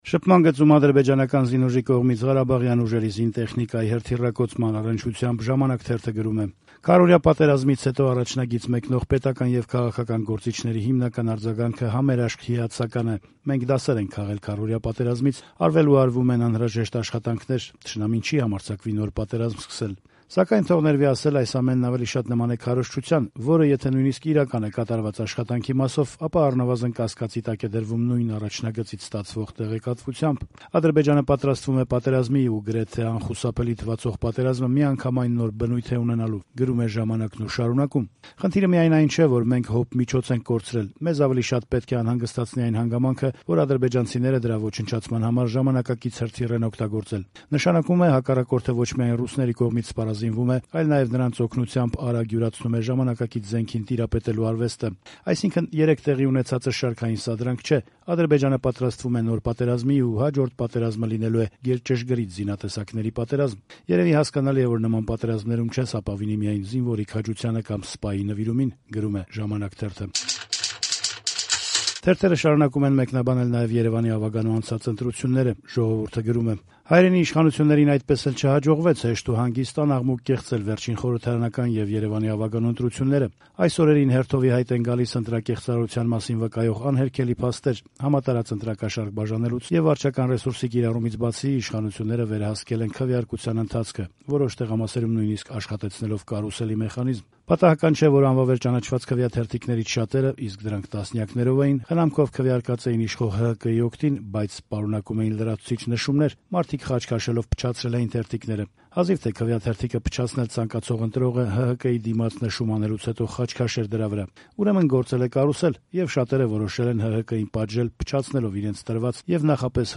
Մամուլի տեսություն